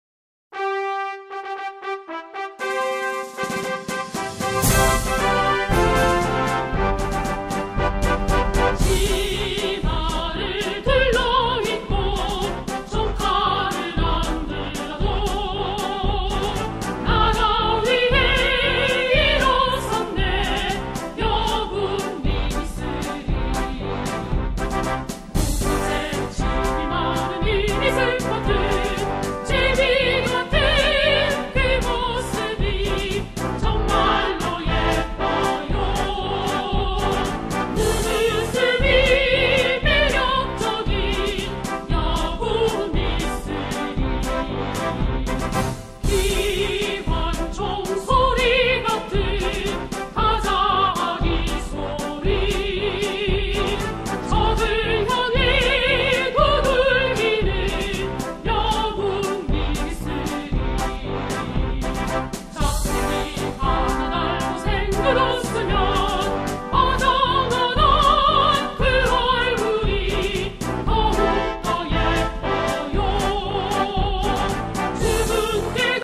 해병대군가음악
▲ 클릭하시면 군가가 재생됩니다.